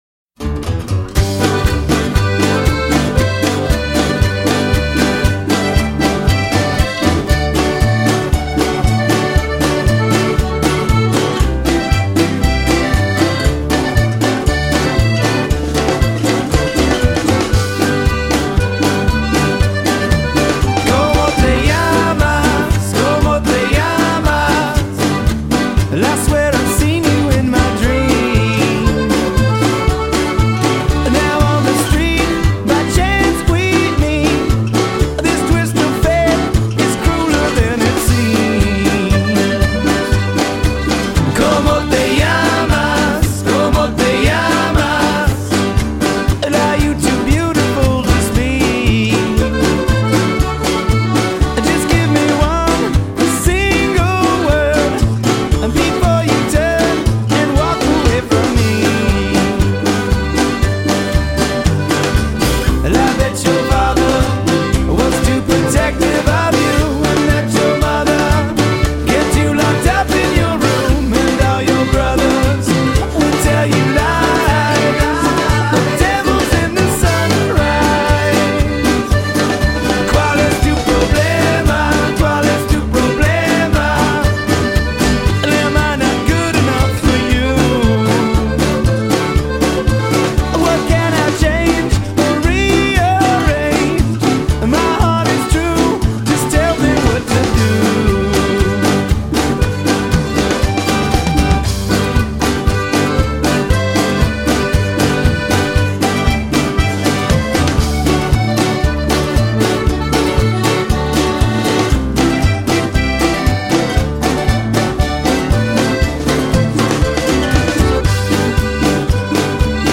A bit of tasty Tejano from the punk band with a double major